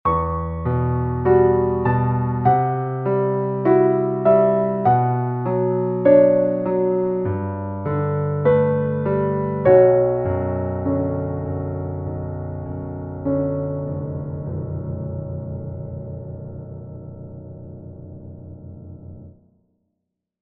• 人声数拍
• 大师演奏范例